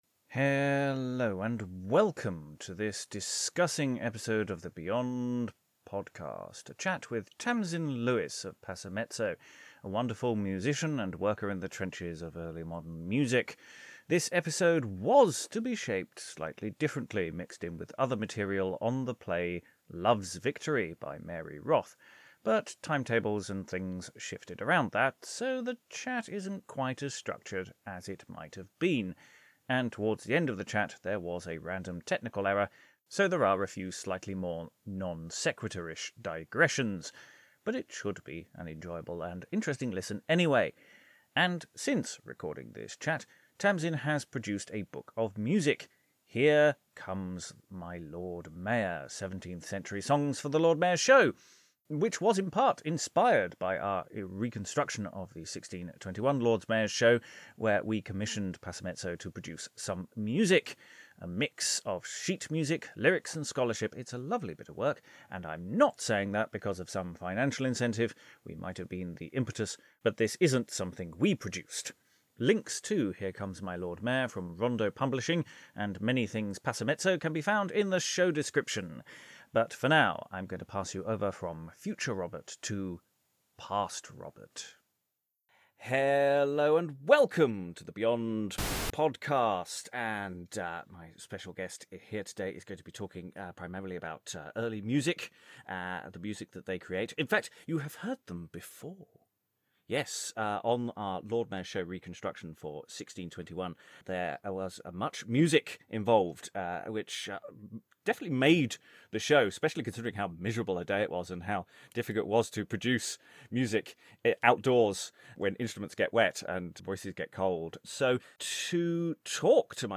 A chat about early modern music